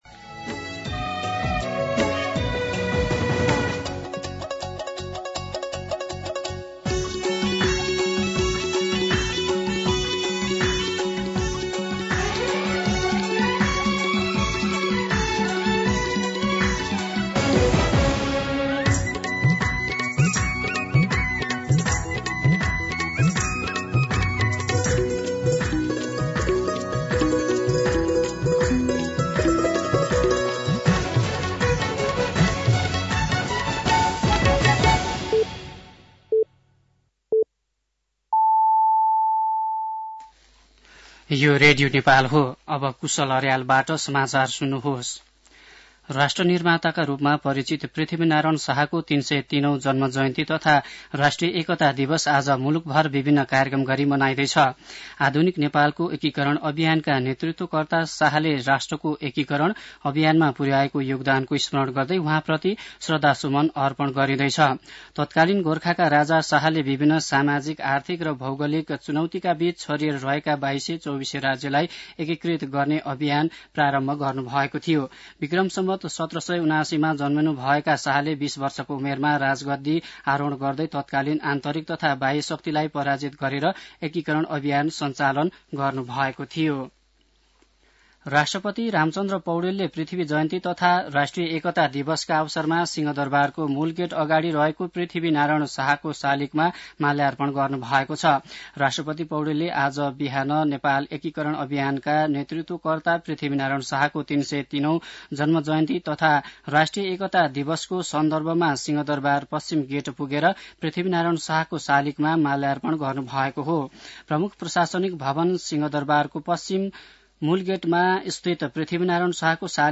दिउँसो १ बजेको नेपाली समाचार : २८ पुष , २०८१
1pm-News-09-27.mp3